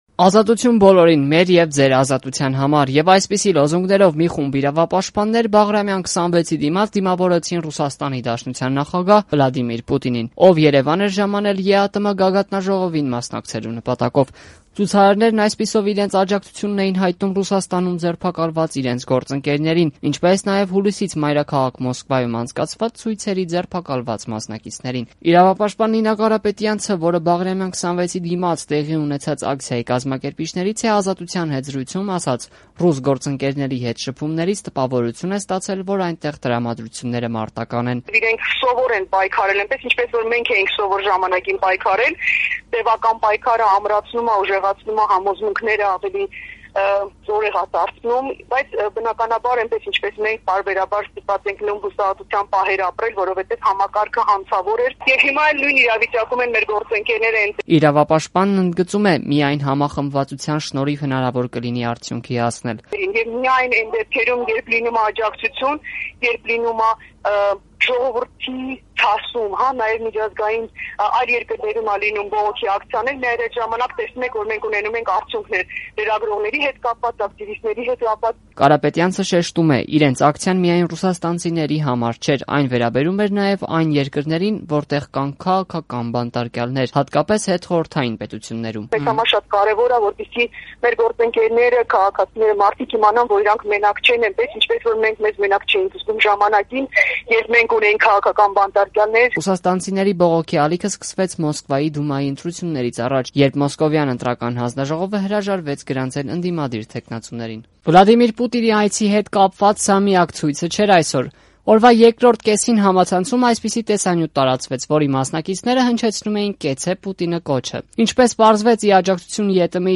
Ռեպորտաժներ
Բողոքի ակցիա Երևանում ի պաշտպանություն Ռուսաստանում ձերբակալված իրավապաշտպանների